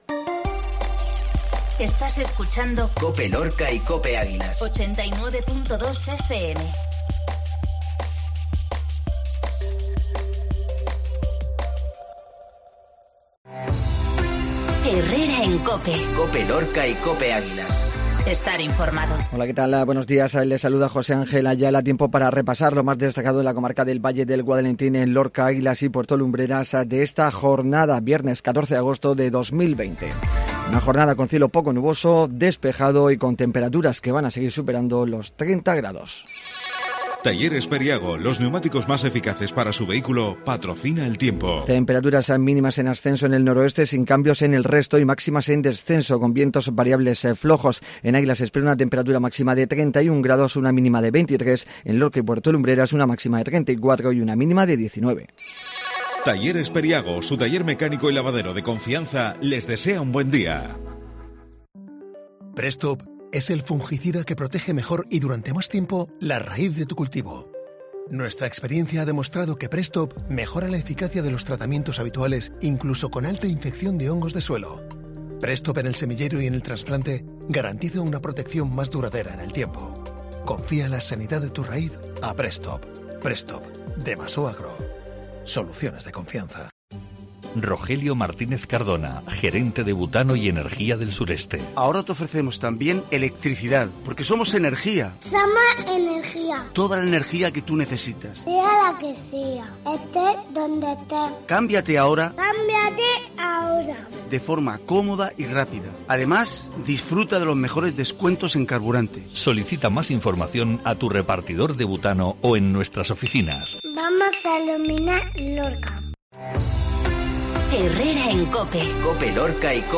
INFORMATIVO MATINAL VIERNES 1408